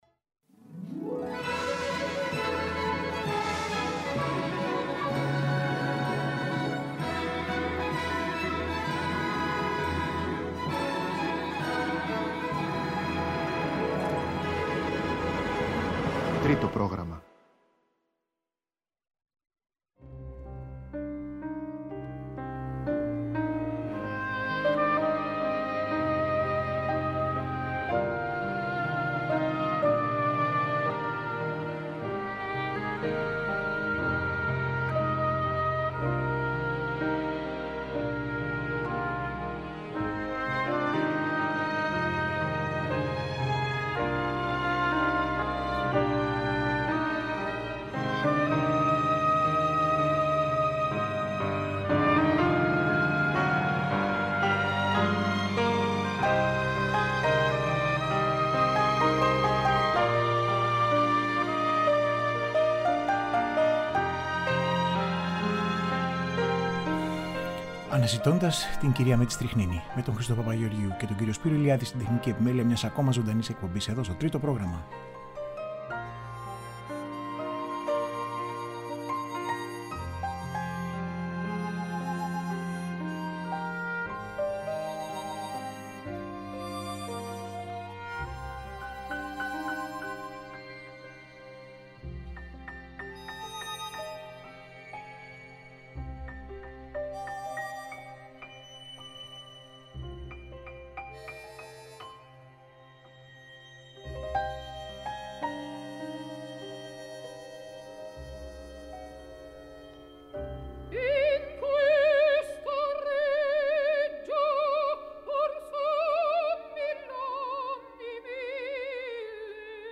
Μία σειρά πέντε ωριαίων εκπομπών με ηχογραφήσεις και αφηγήσεις όπως αυτές αποτυπώνονται μέσα από την πλούσια βιβλιογραφία για την μεγάλη αυτή προσωπικότητα της κοινωνικής ζωής και της τέχνης του 20ου αιώνα.